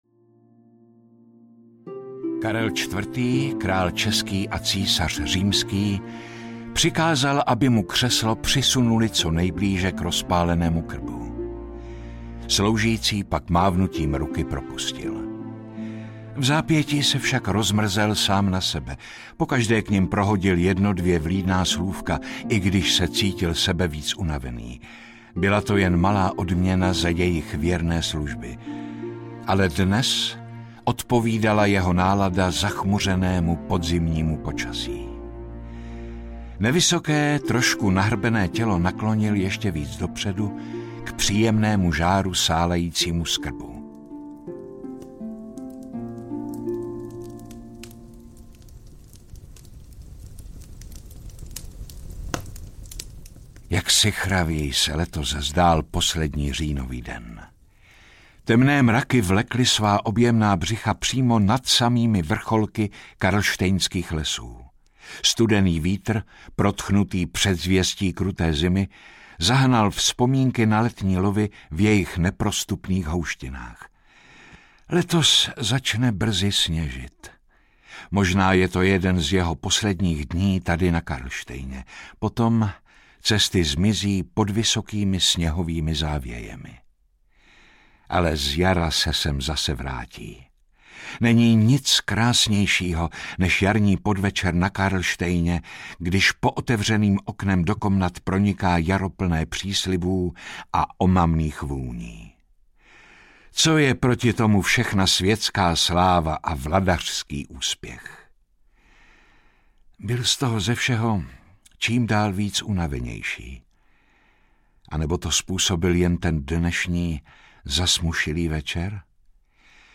Anna Česká audiokniha
Ukázka z knihy